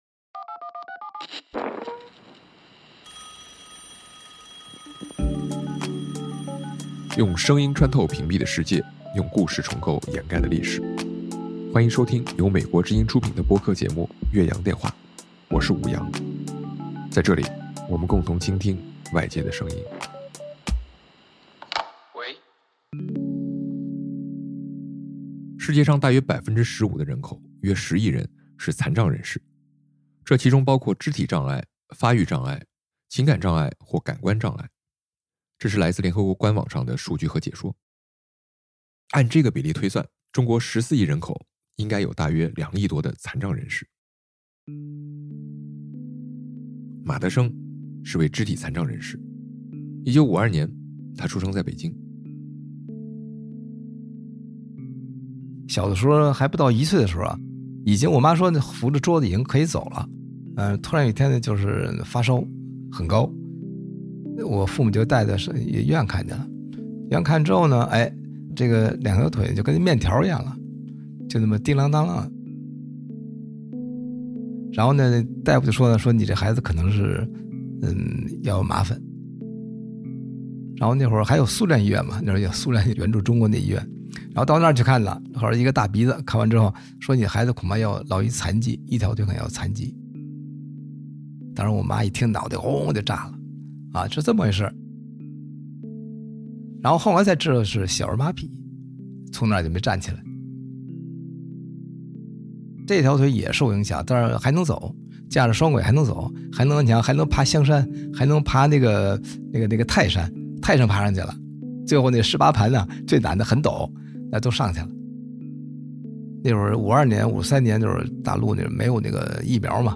本期嘉宾：马德升